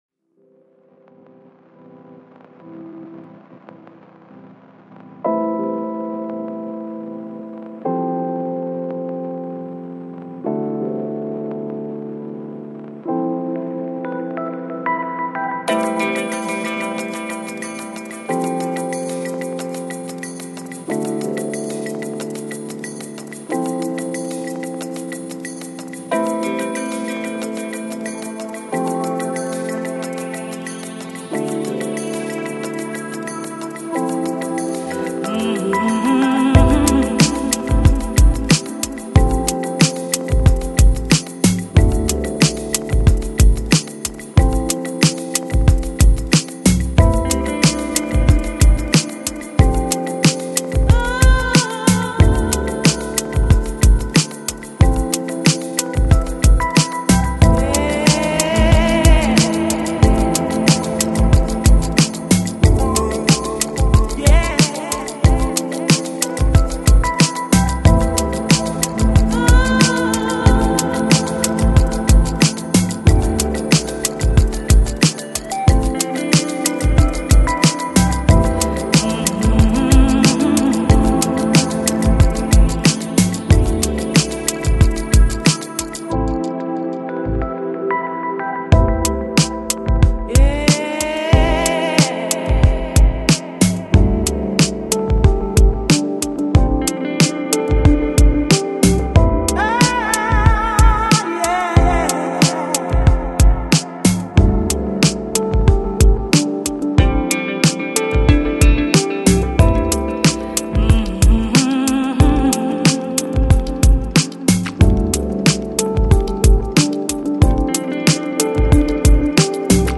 Lounge, Balearic, Downtempo, Smooth Jazz Год издания